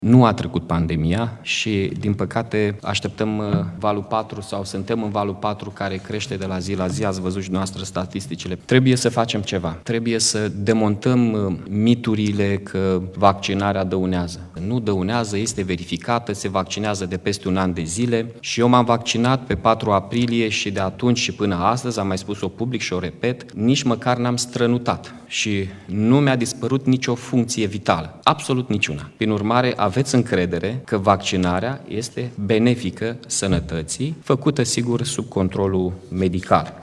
Îngrijorat de de efectele valului 4 al pandemiei, primarul orasului Iaşi, Mihai Chirica, face apel la cetăţeni să se vaccineze :